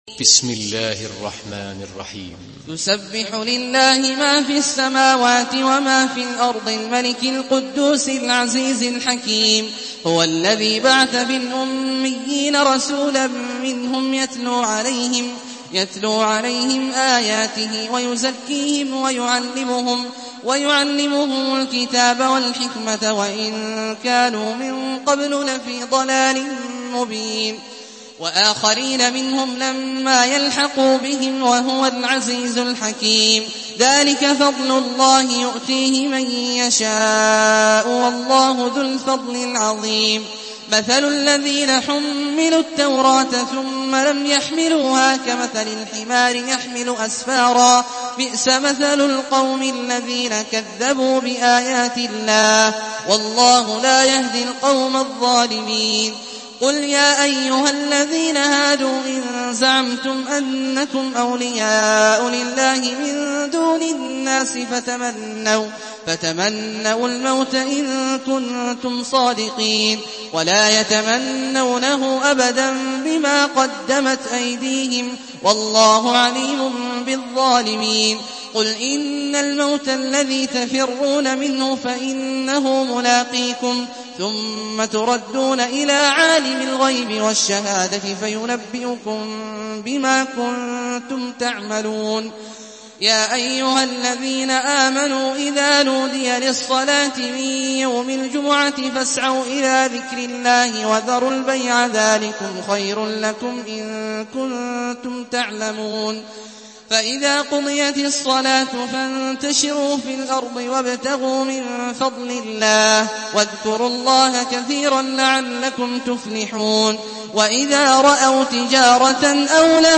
Surah আল-জুমু‘আ MP3 by Abdullah Al-Juhani in Hafs An Asim narration.
Murattal